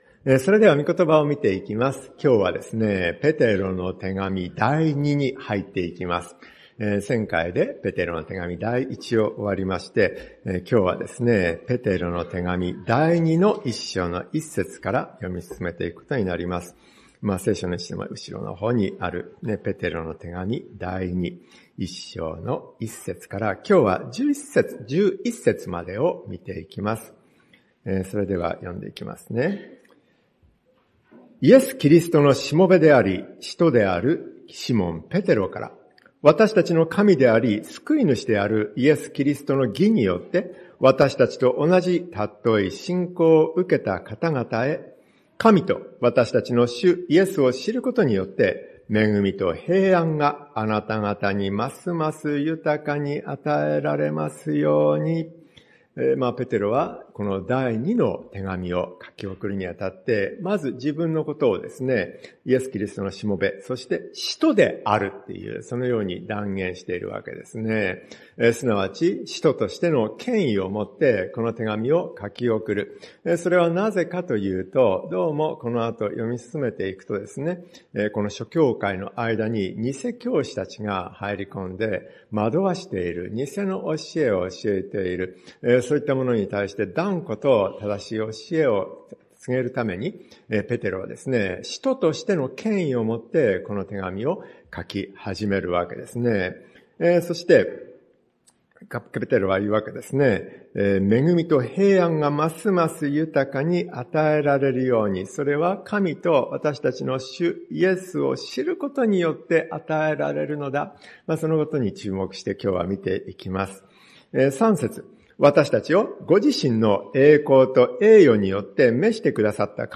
恵みと平安を得る 説教者